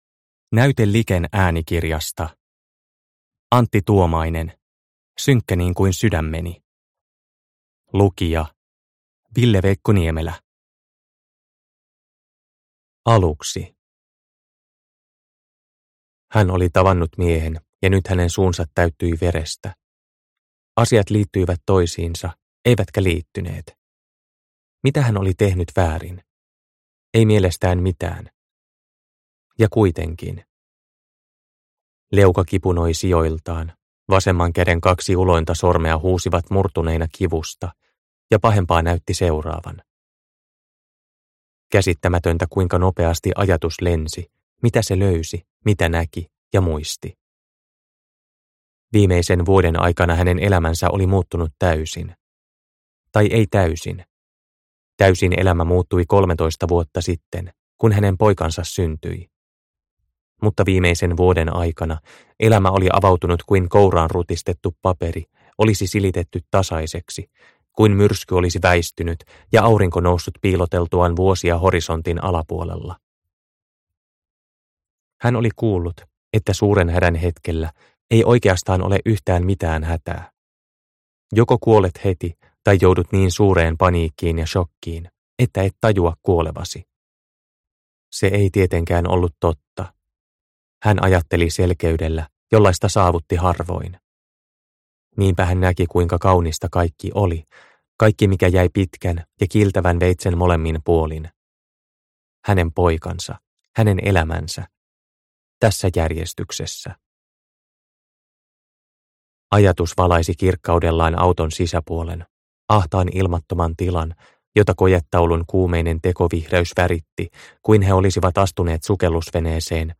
Synkkä niin kuin sydämeni – Ljudbok – Laddas ner